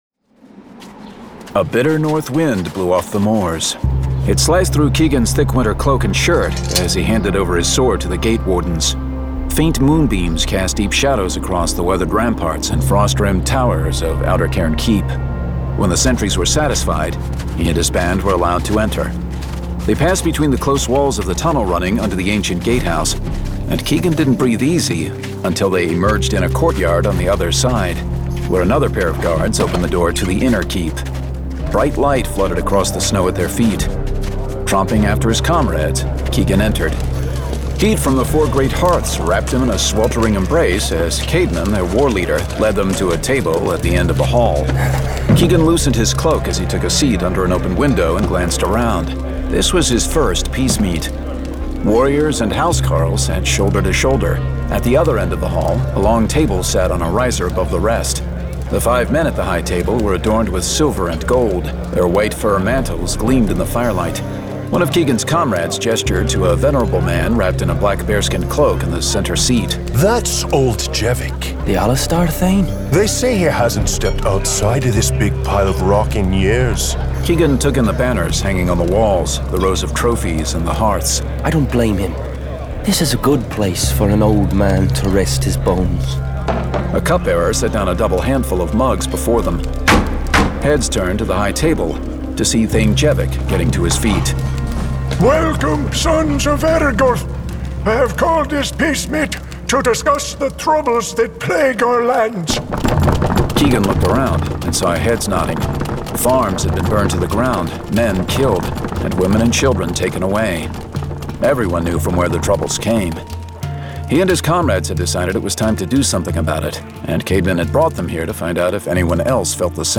Shadow Saga 2: Shadow's Lure [Dramatized Adaptation]